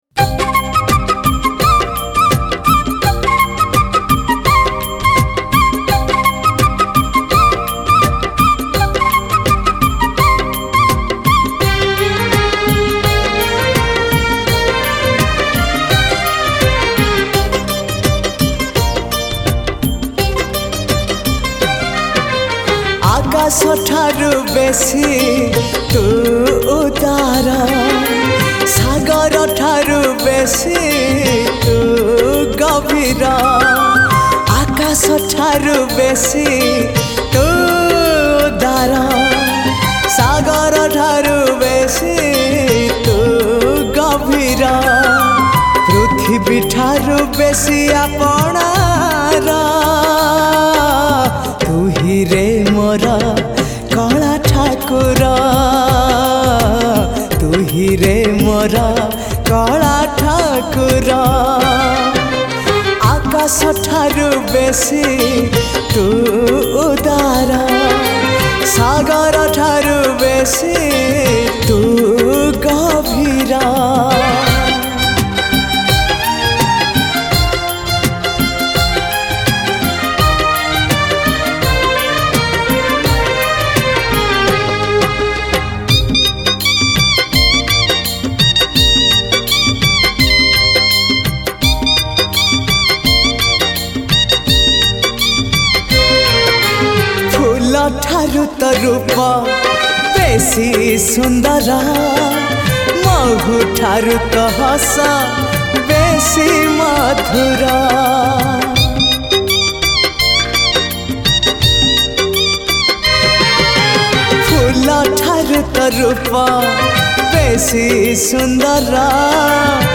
Odia Best Bhajan Song